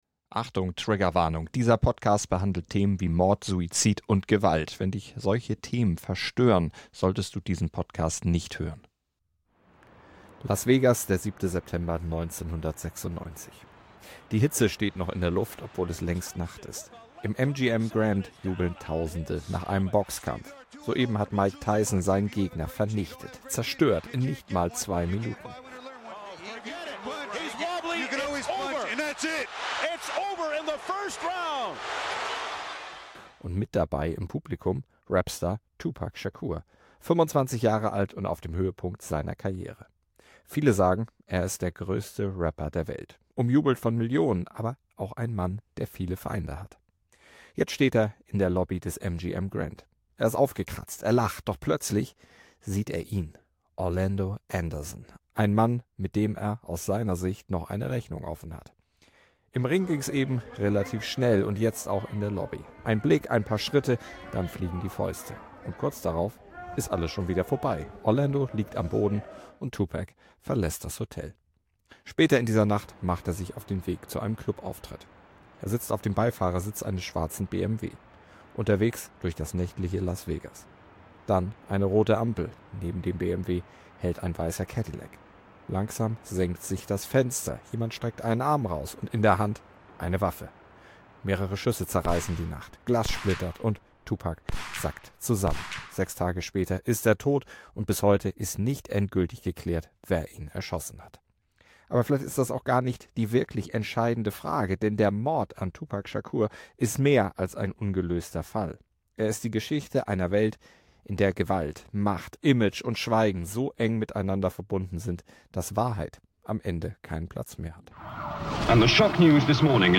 O-Töne wurden in diesem Podcast mit Hilfe von KI-Stimmmen overvoiced.